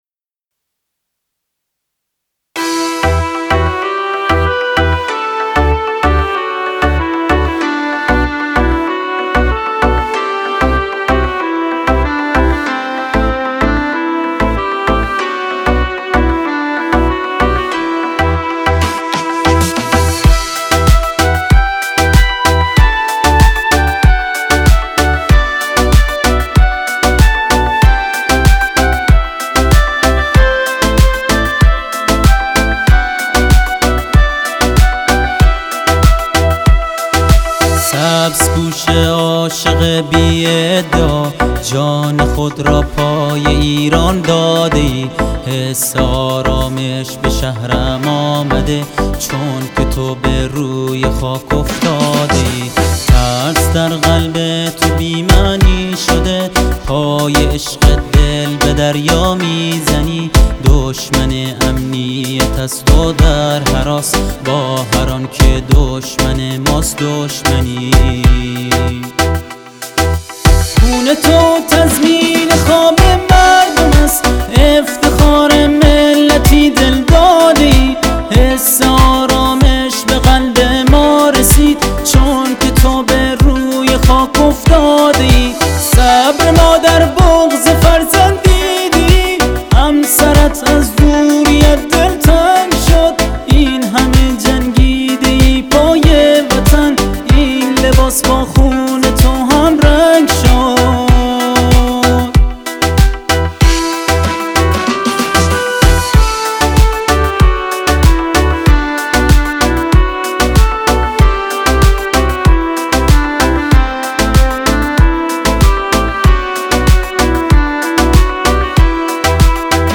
بلوچی